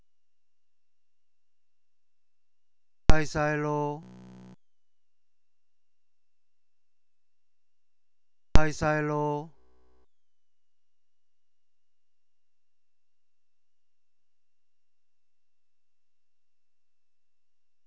它们使用有源模拟麦克风、并且在上电后100uS 后麦克风输出可激活。
但在 ADC3101‘~麦克风数据后，麦克风输出会被屏蔽一段时间，特别是当增益较高时(当增益为32时，麦克风输入的开始被屏蔽，在录制的文件中，扬声器会告诉‘Hi~ Silo’，但可能只听到 lo’部分。)
Q3>当听到录制文件时、是否可以看到弹出噪声、如何改进？